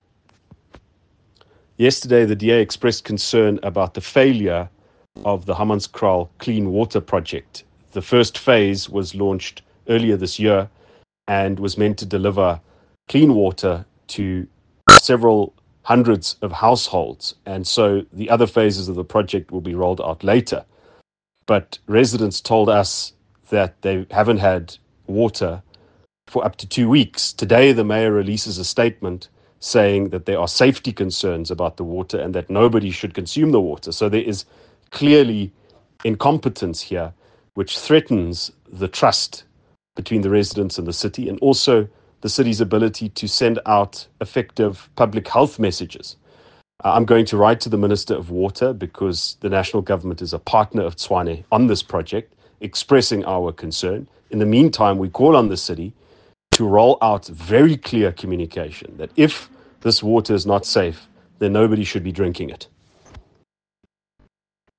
Note to Editors: Please find an English soundbite by Ald Cilliers Brink